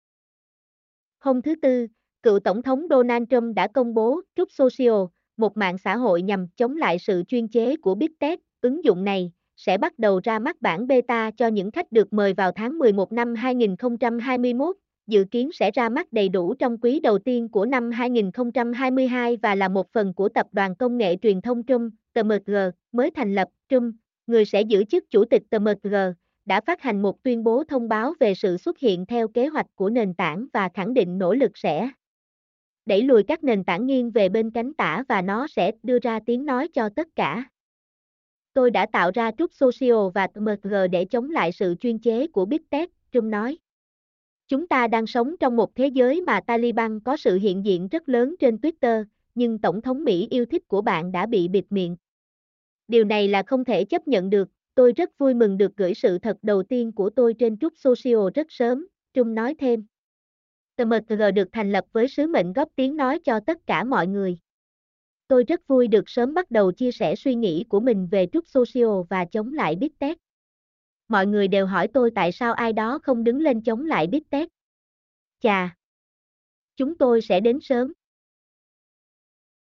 mp3-output-ttsfreedotcom-5.mp3